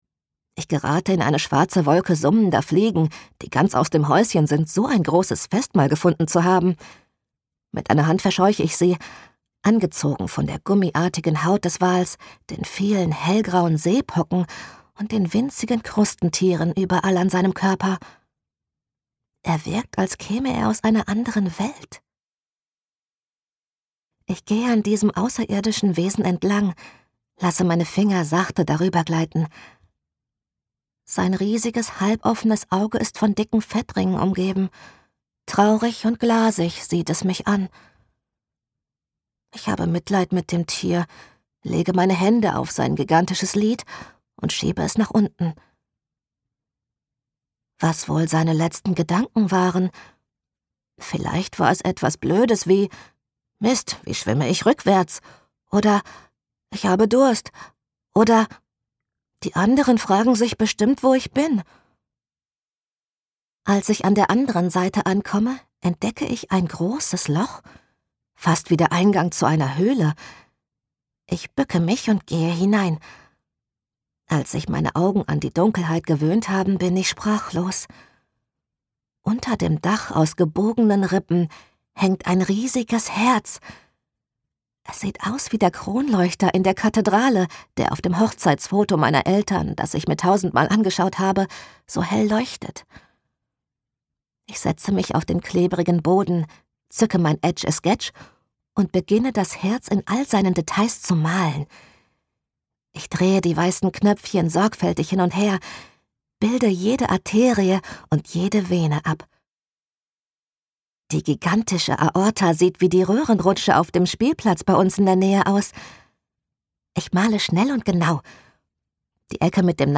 Hörbuch - KIND